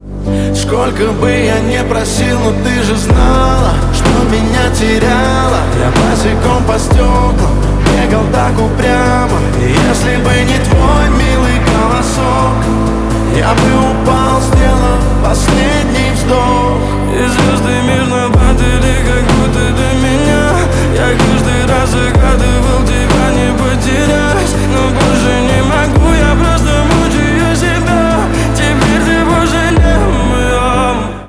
• Качество: 128, Stereo
гитара
лирика
грустные
красивый мужской голос
мелодичные
дуэт
пианино